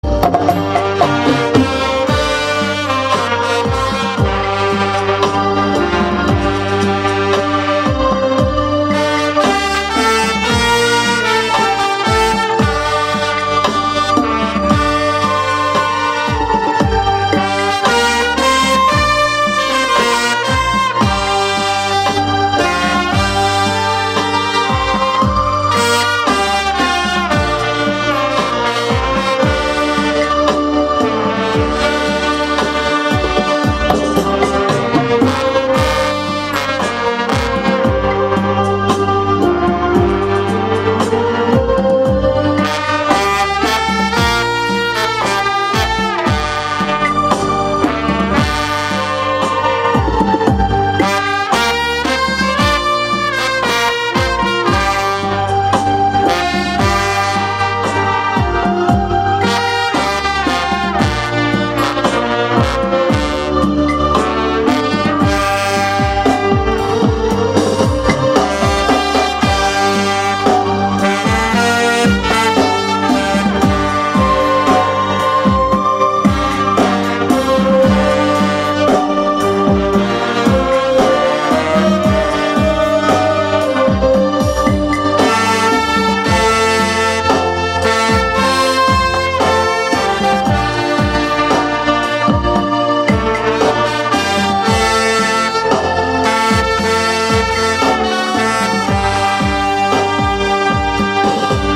sang trọng, sâu lắng và đầy cảm xúc
bản nhạc không lời chất lượng cao